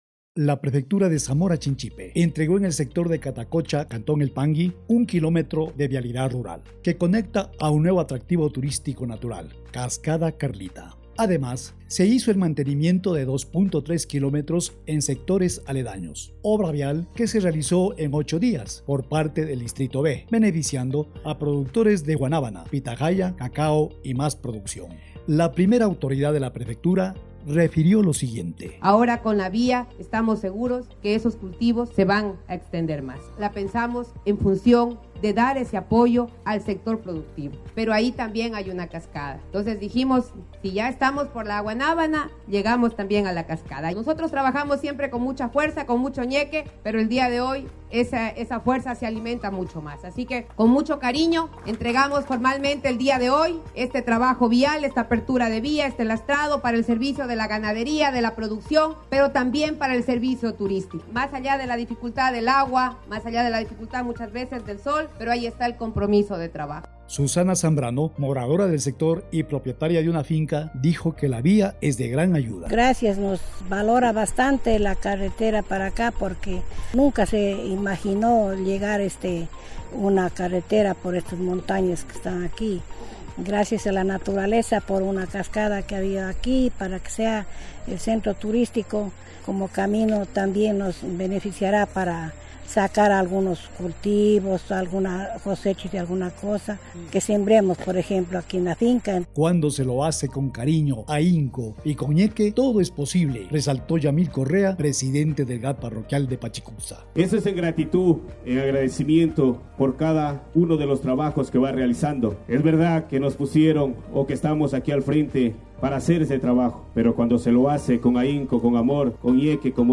NOTA DE RADIO